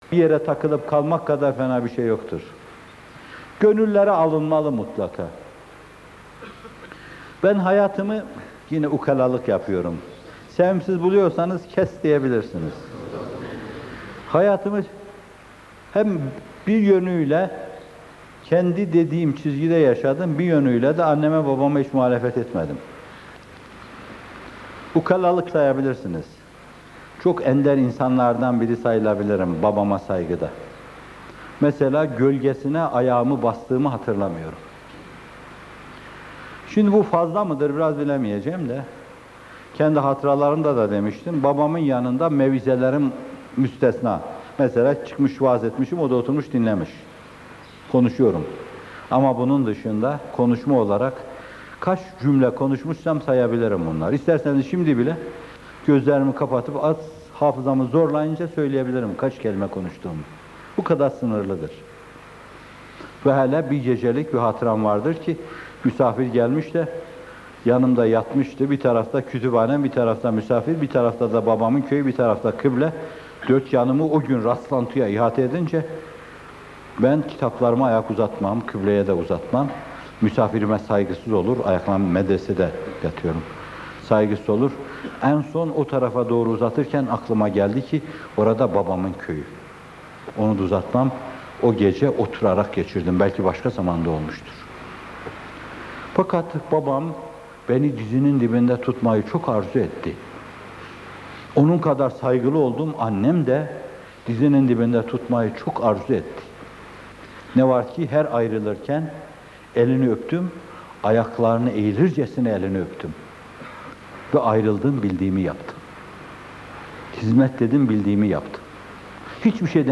Hizmet Neredeyse Biz Oradayız - Fethullah Gülen Hocaefendi'nin Sohbetleri
Muhterem Hocaefendi, hizmete koşma ve anne babaya hürmette kusur etmeme dengesini anlatıyor.